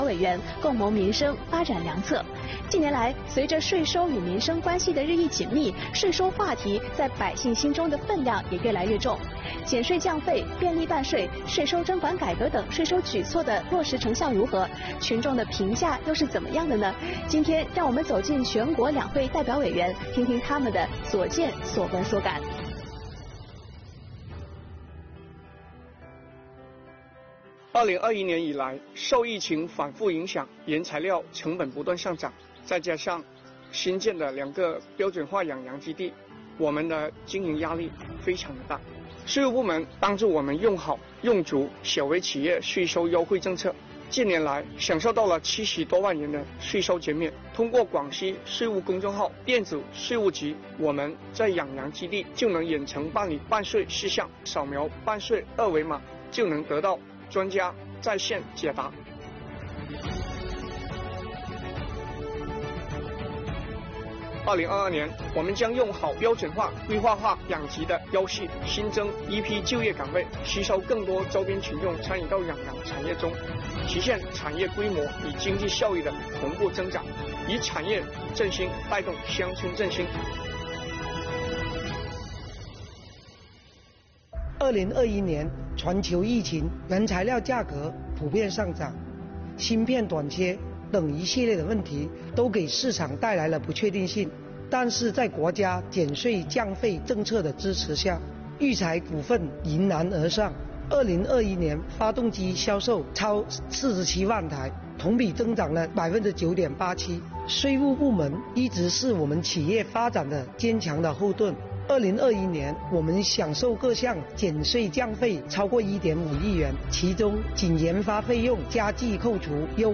近年来，随着税收与民生关系的日益紧密，税收话题在百姓心中的分量也越来越重。减税降费、便利办税、税收征管改革等税收举措的落实成效如何、群众的评价怎样，今天，就让我们一起走近全国两会代表委员，听听他们的所见所闻所感吧！